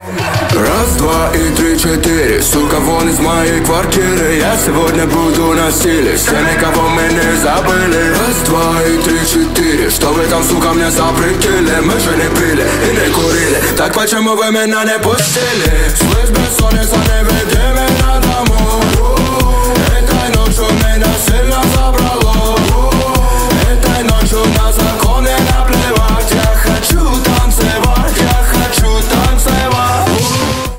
громкие
зажигательные
заводные
танцы